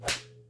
HardSlap.ogg